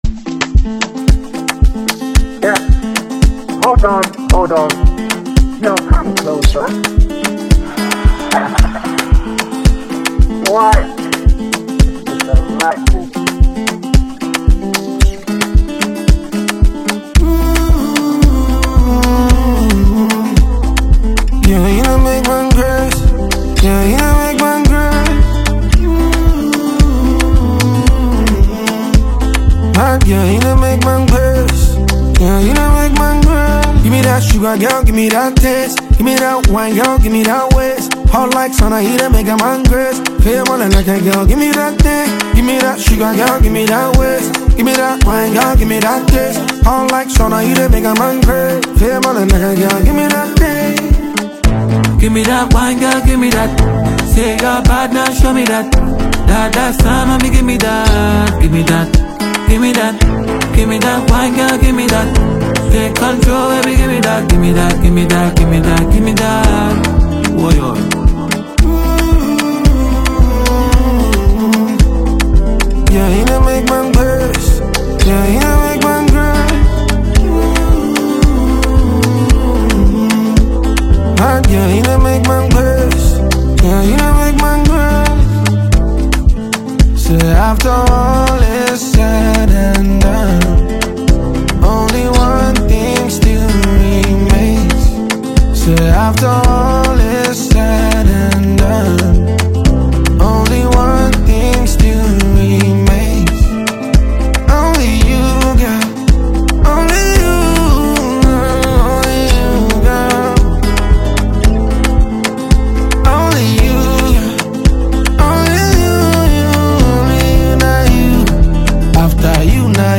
• Genre: Afrobeats / R&B